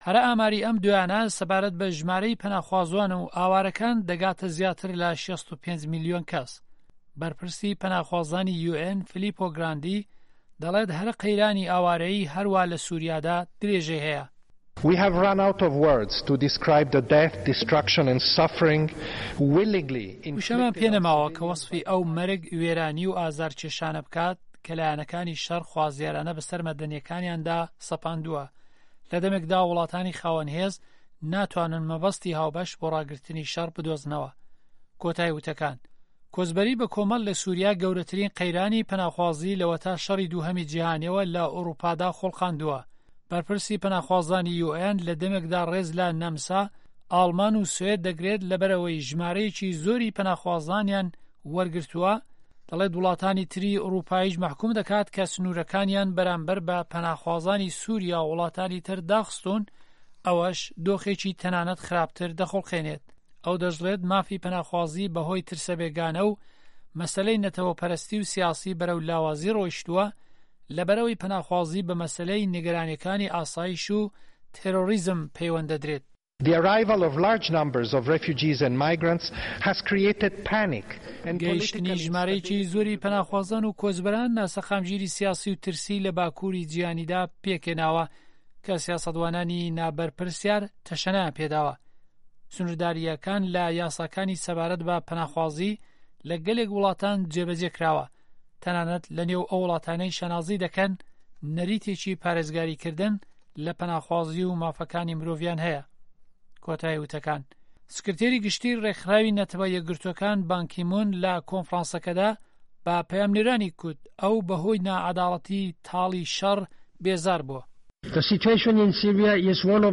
سکرتێری گشتی ڕێکخراوی نه‌ته‌وه‌ یه‌کگرتووه‌کان بان کی موون له‌ کونفڕانسه‌که‌دا به‌ په‌یامنێرانی گوت ئه‌و به‌ هۆی ناعه‌داڵه‌تی تاڵی شه‌ڕ بێزار بووه‌.